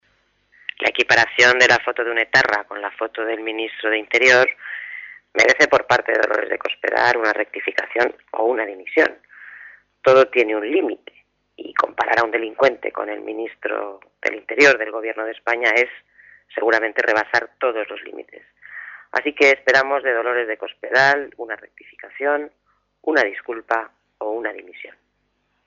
Cortes de audio de la rueda de prensa
Audio-Valenciano.mp3